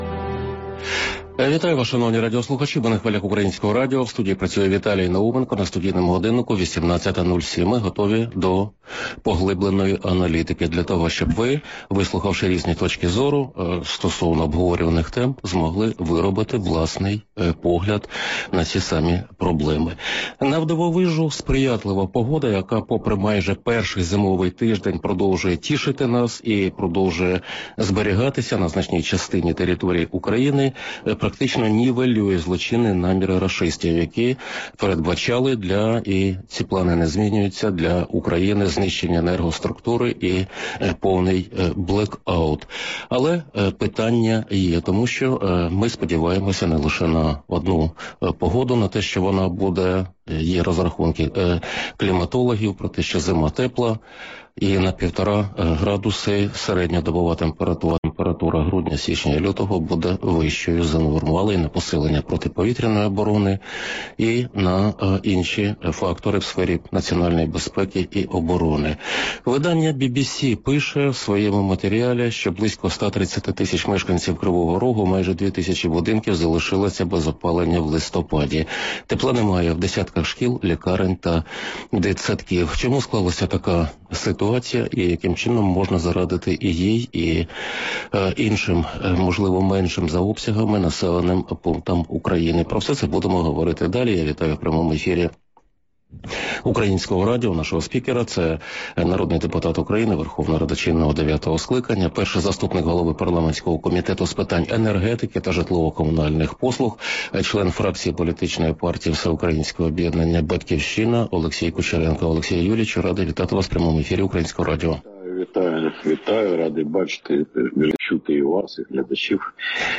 Україна не використовує повний потенціал імпорту електроенергії через неефективні рішення регулятора. Про це заявив народний депутат Олексій Кучеренко в ефірі «Українського радіо».